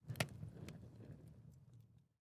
fire_crackle3.ogg